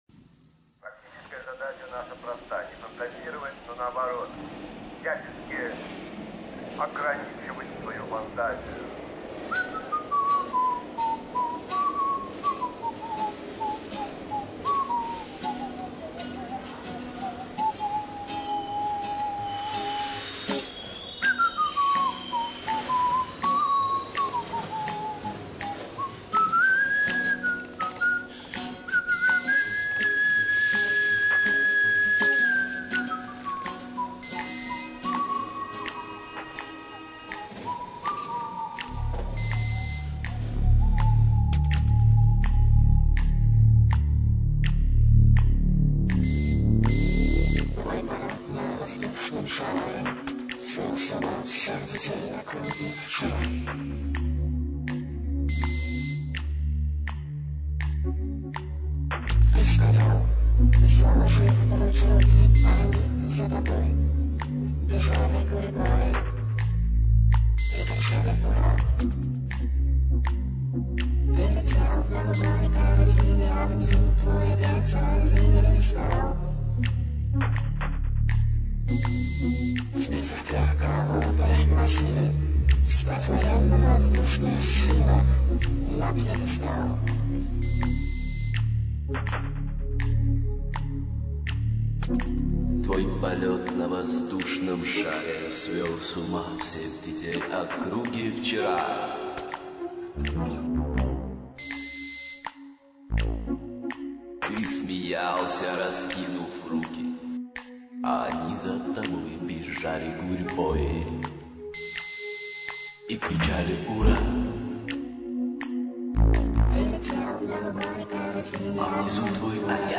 вокал, свист, акустическая гитара
ритм-бокс, синтез-вокал
Всю композицию (mono, 40 kbps, 946 kb) вы можете скачать